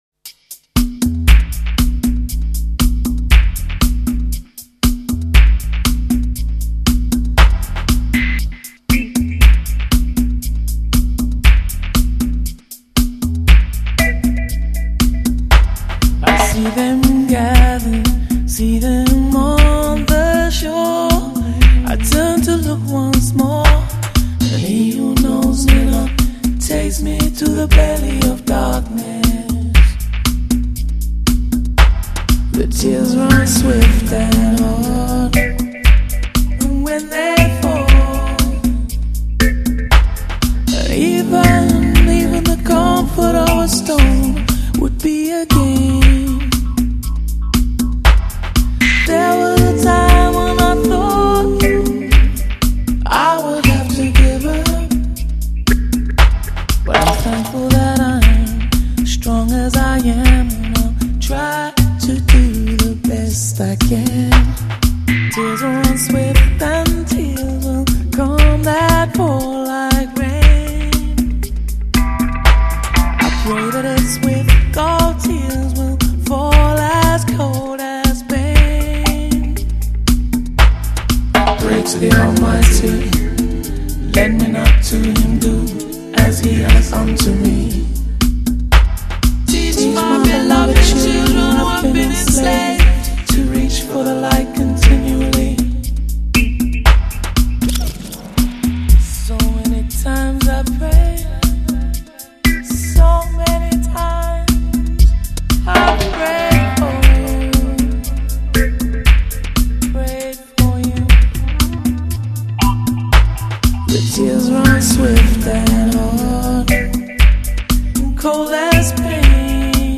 SOUL 又带点 JAZZ